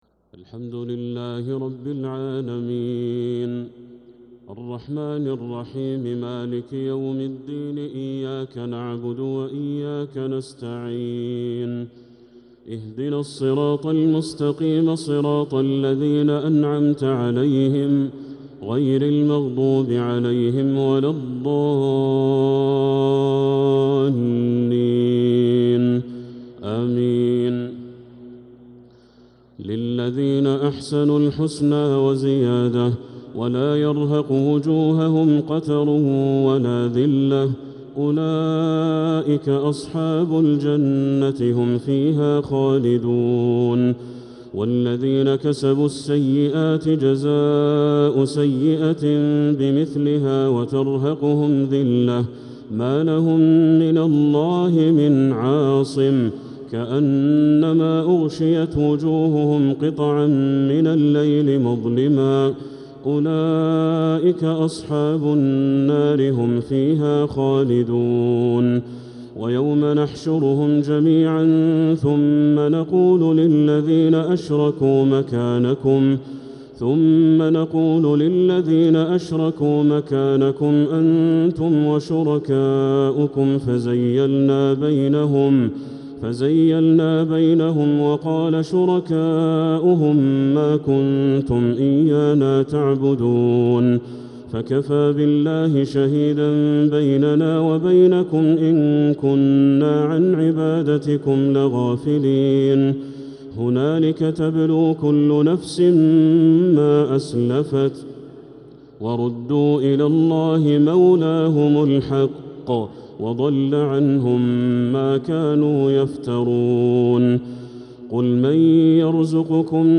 تراويح ليلة 15 رمضان 1446هـ من سورة يونس {26-109} | Taraweeh 15th Ramadan niqht 1446H Surat Yunus > تراويح الحرم المكي عام 1446 🕋 > التراويح - تلاوات الحرمين